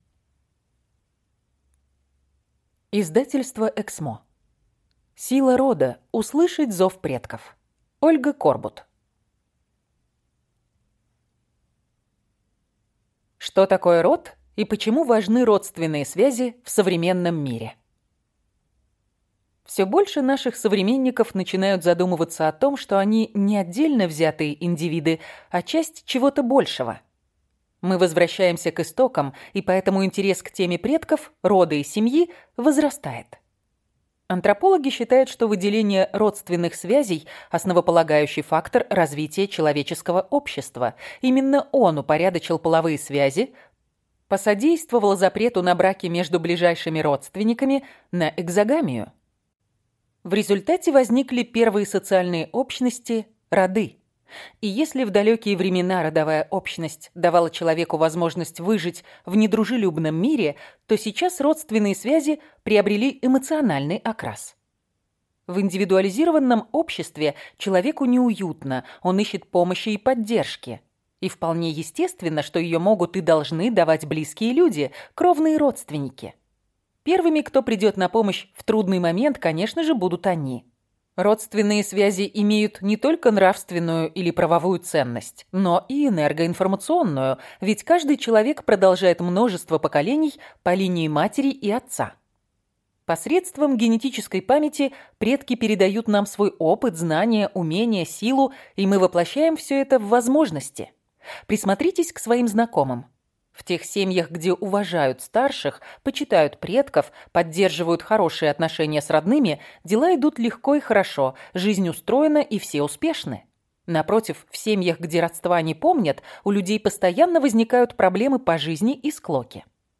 Аудиокнига Сила рода. Услышать зов предков | Библиотека аудиокниг